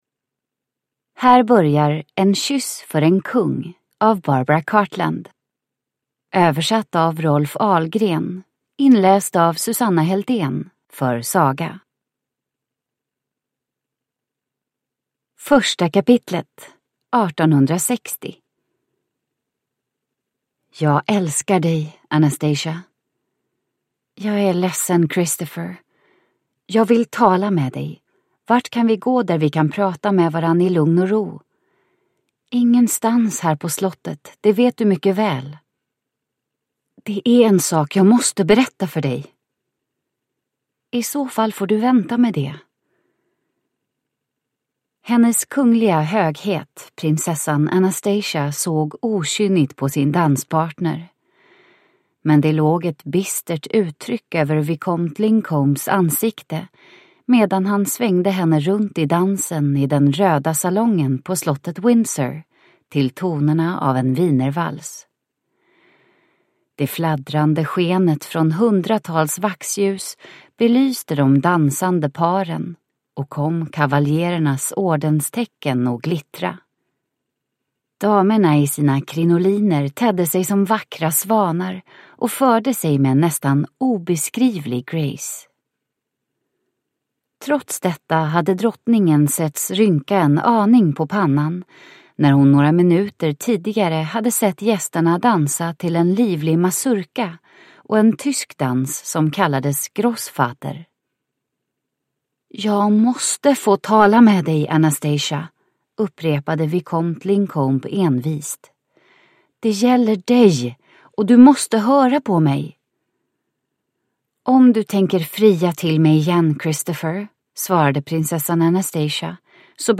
En kyss för en kung – Ljudbok – Laddas ner